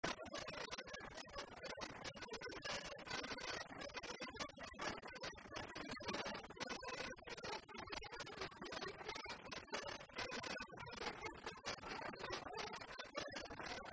Divertissements d'adultes - Couplets à danser
danse : branle : courante, maraîchine
Pièce musicale inédite